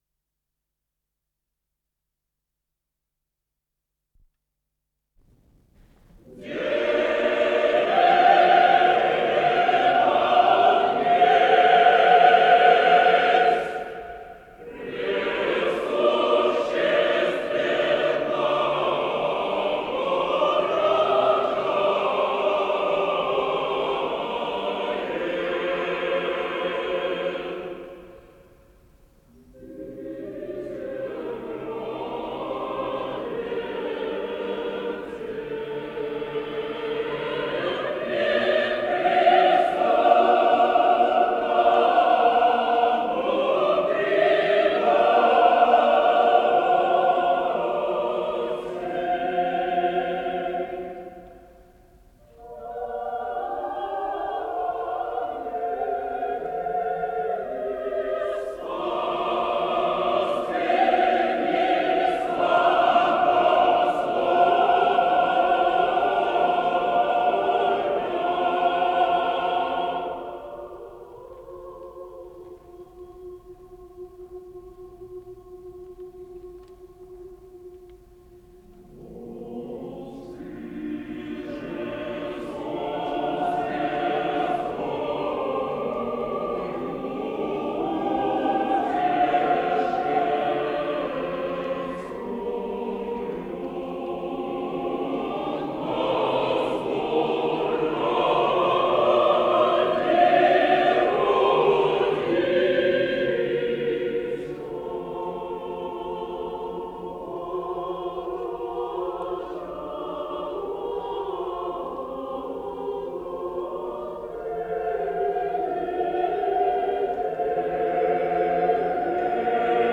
Дева Днесь (знаменного роспева), кондак на рождество христово
ИсполнителиГосударственный московский хор
ВариантДубль стерео